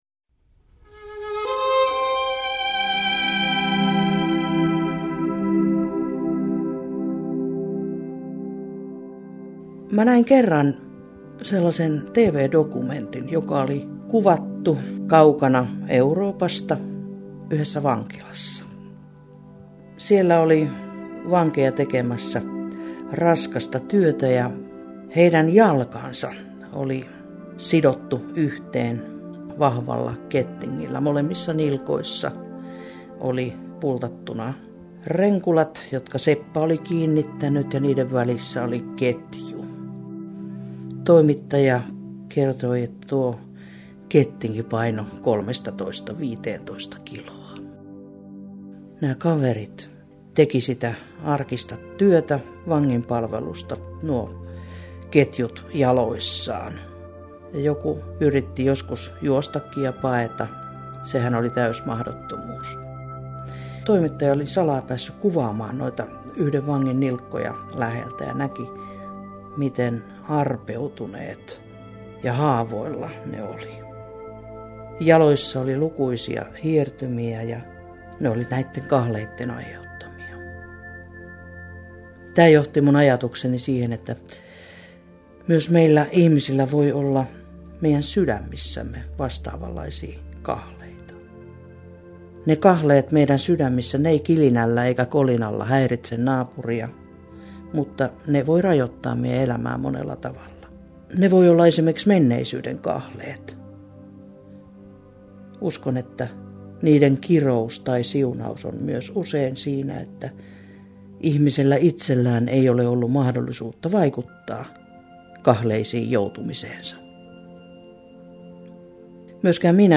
Radio Dei lähettää FM-taajuuksillaan radiohartauden joka arkiaamu kello 7.50. Hartaus kuullaan uusintana iltapäivällä kello 17.05.